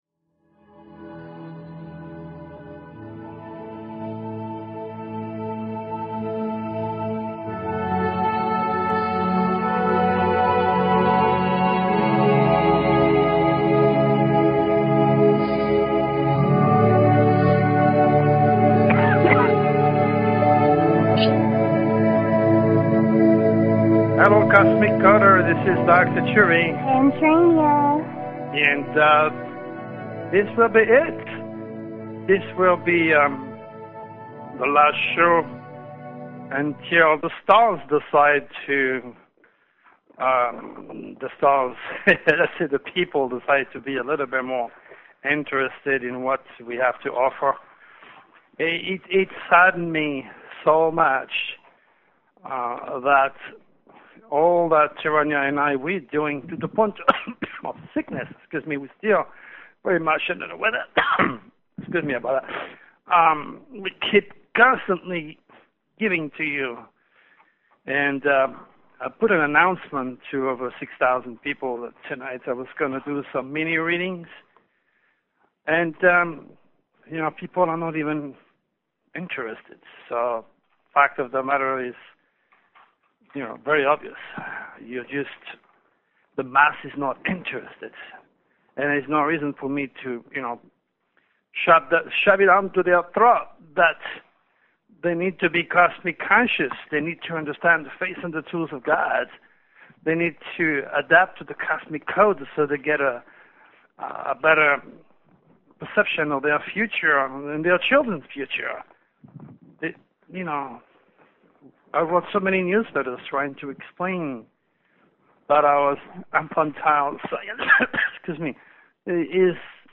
Talk Show Episode, Audio Podcast, The_Cosmic_Code and Courtesy of BBS Radio on , show guests , about , categorized as